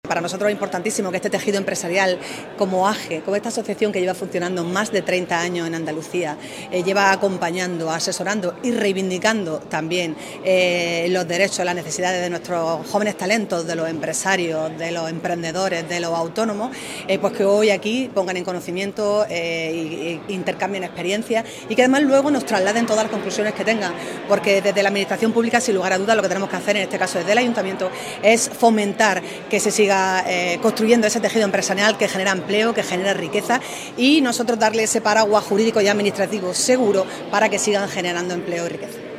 ALCALDESA-AJE.mp3